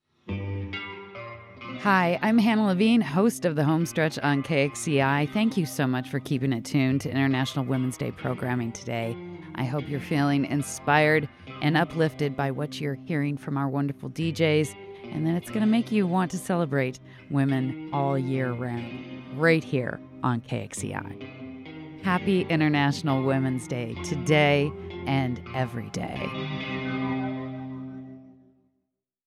In honor of International Women’s day, we bring you the voices of four remarkable women, each contributing to the tapestry of our community in unique and powerful ways.